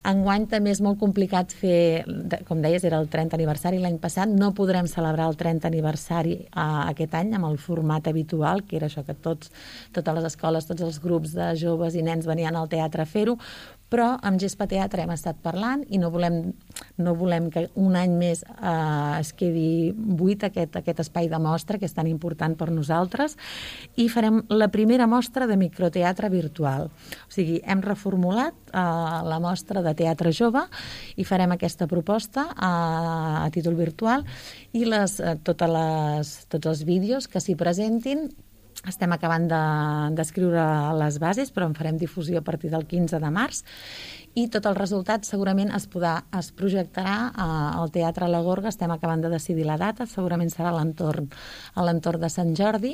Núria Botellé, regidora de Cultura de l’Ajuntament de Palamós, així ho va explicar a Ràdio Palamós.